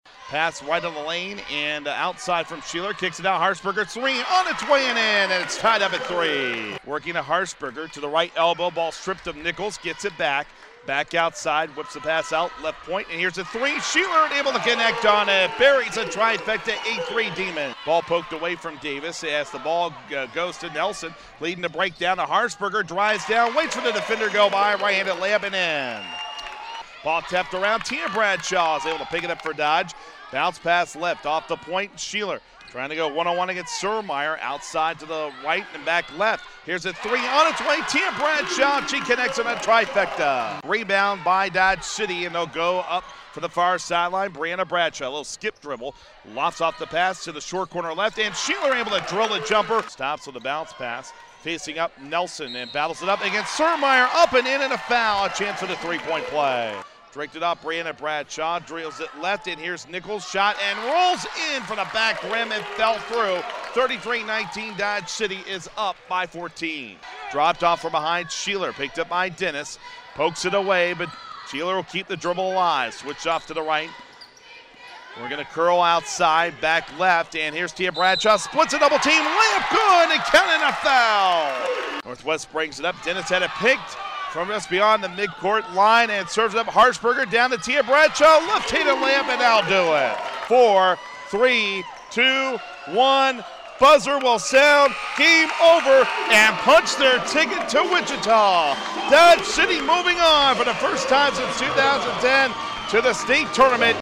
Game Highlights